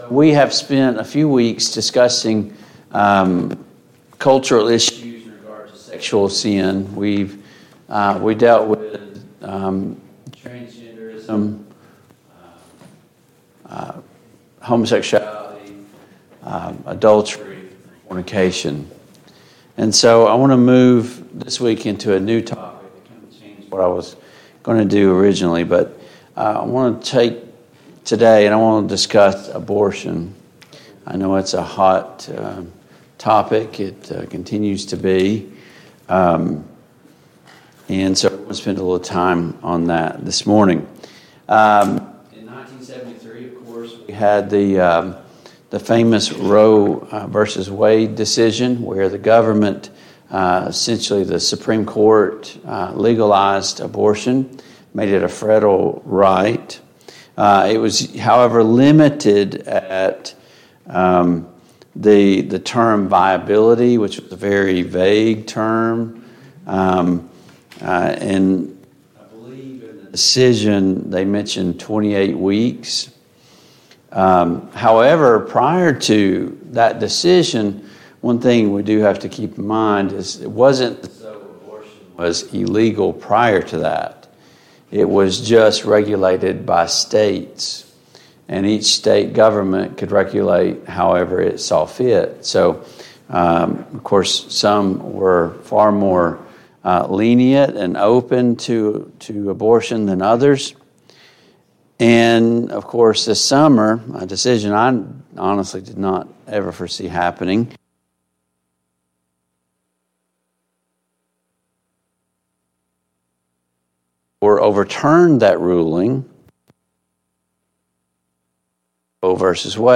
Service Type: Sunday Morning Bible Class Topics: Abortion , murder , The right to life